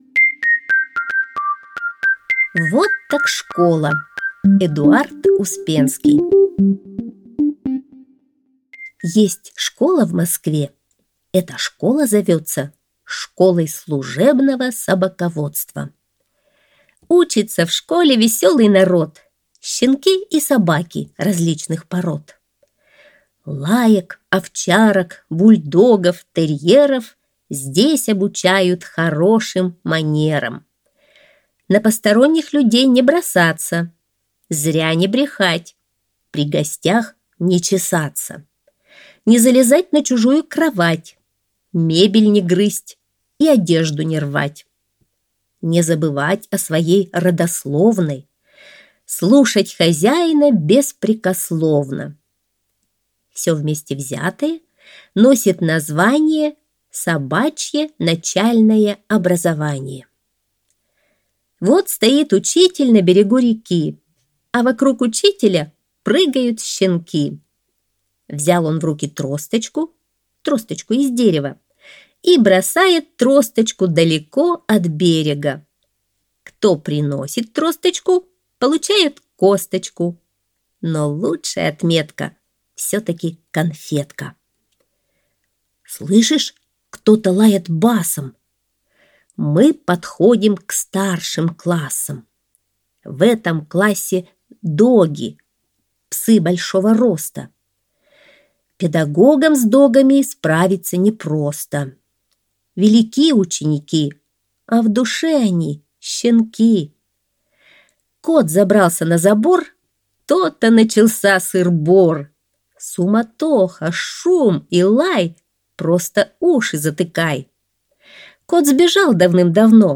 Аудиосказка «Вот так школа»